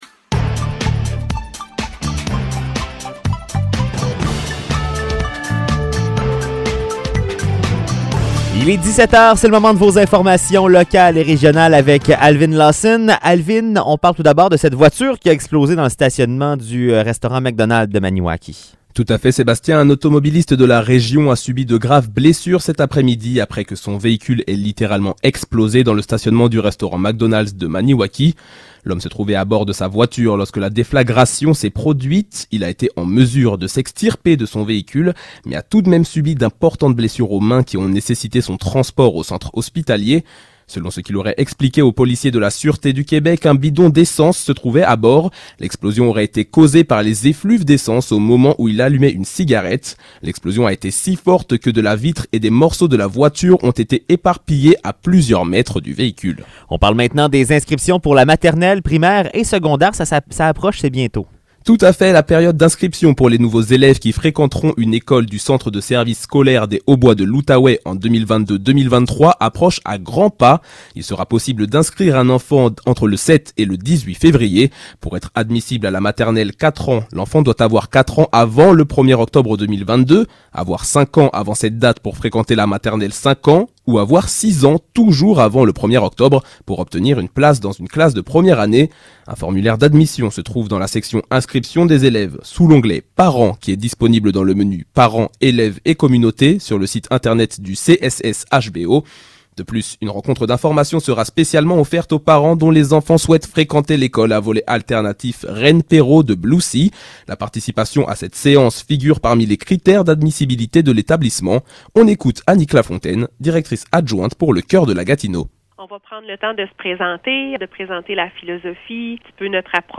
Nouvelles locales - 1er février 2022 - 17 h